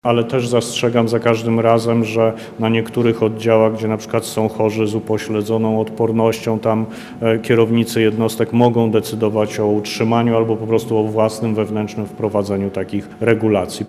To największa zmiana” – mówi minister Adam Niedzielski.